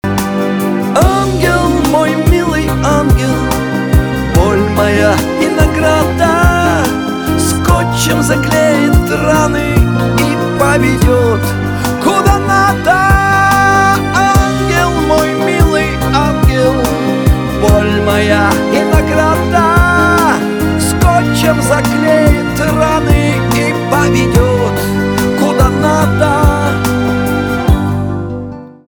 шансон
чувственные
скрипка , гитара , битовые